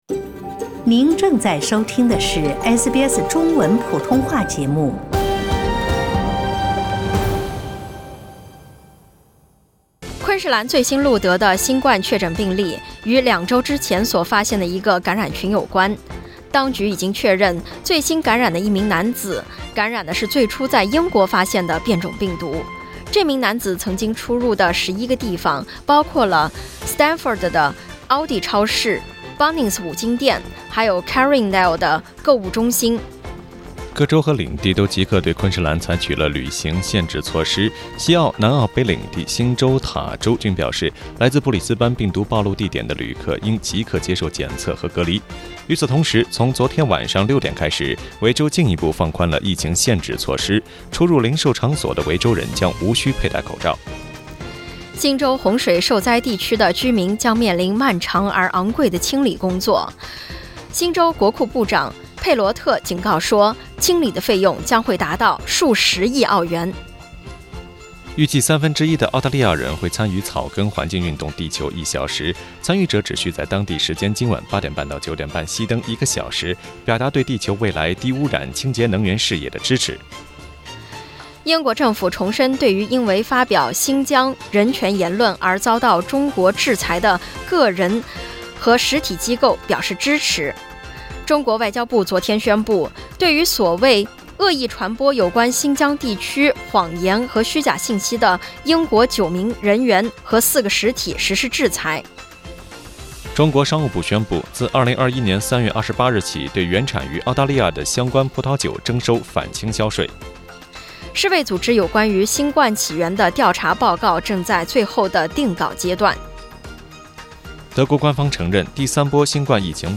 SBS早新聞（3月27日）